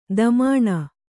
♪ damāṇa